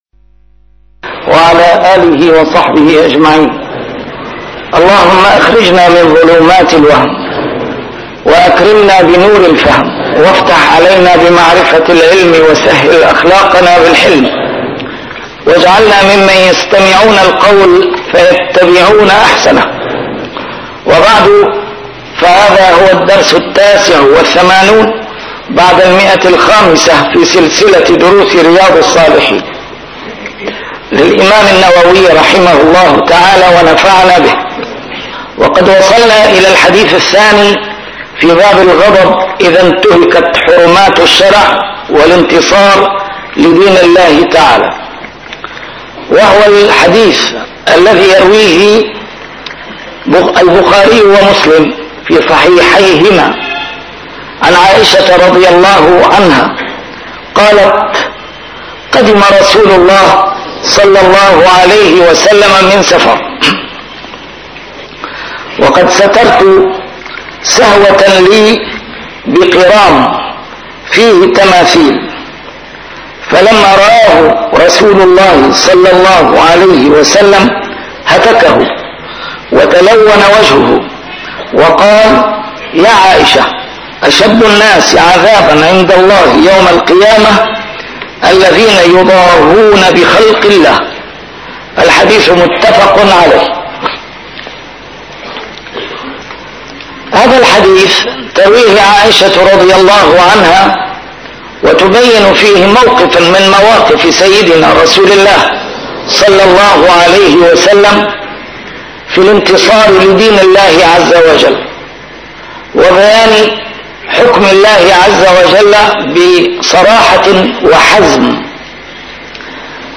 A MARTYR SCHOLAR: IMAM MUHAMMAD SAEED RAMADAN AL-BOUTI - الدروس العلمية - شرح كتاب رياض الصالحين - 589- شرح رياض الصالحين: الغضب